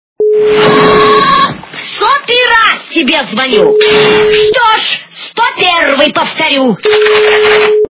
При прослушивании Злая жена - в 100-й раз тебе звоню, что ж 101 повторю! качество понижено и присутствуют гудки.